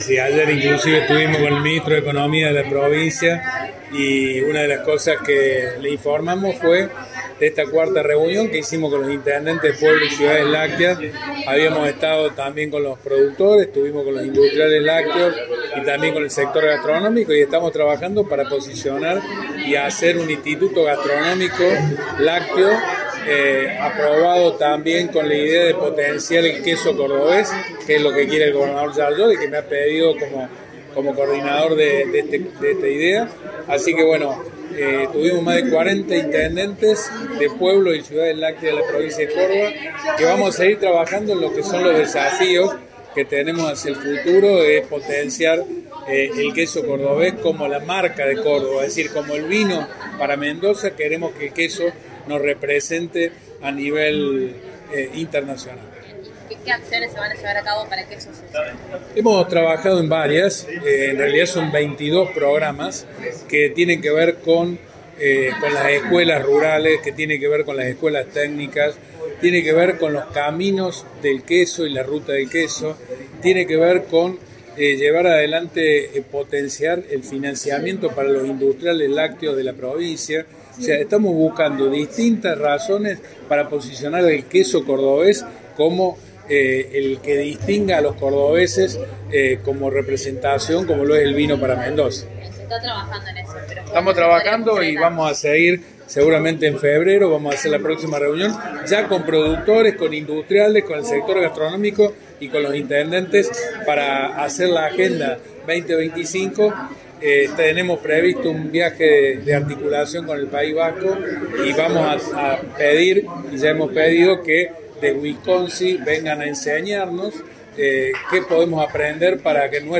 «Ayer incluso incluso estuvimos con el ministro de Economía de la provincia y le informamos de esta cuarta reunión que tuvimos. Estamos trabajando para desarrollar un instituto gastronómico lácteo para posicionar al queso cordobés, que es lo que quiere el gobernador Llaryora», dijo Accastello a los medios.